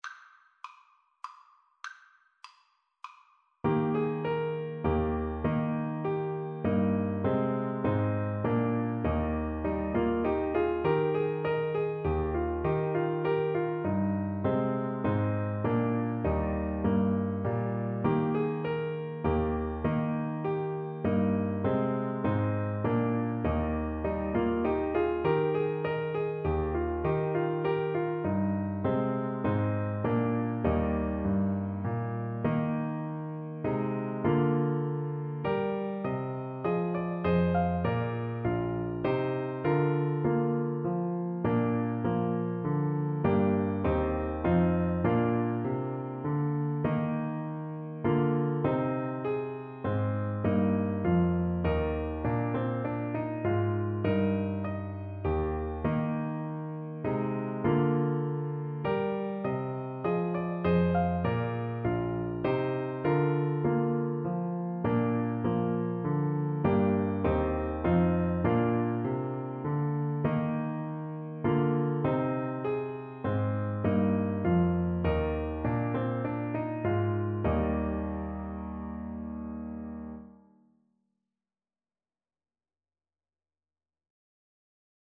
Alto Saxophone
3/4 (View more 3/4 Music)
Moderato = c.100
D5-Eb6
Classical (View more Classical Saxophone Music)